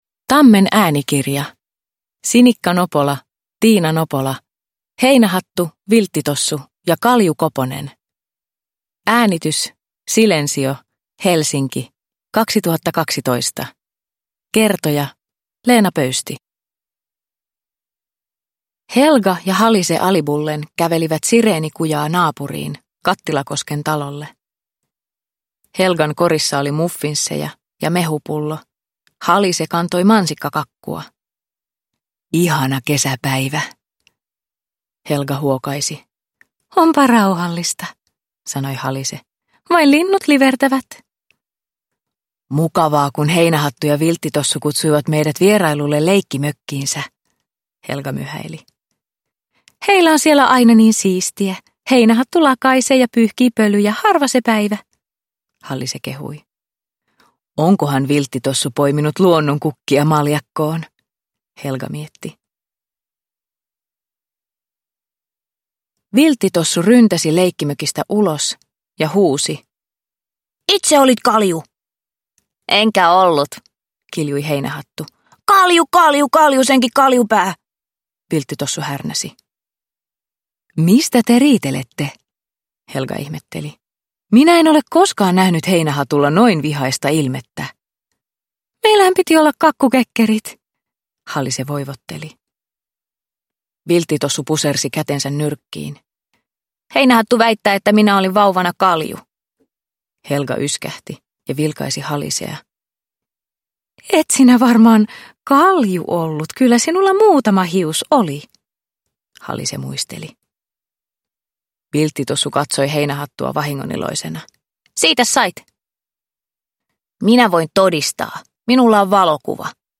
Heinähattu, Vilttitossu ja Kalju-Koponen – Ljudbok – Laddas ner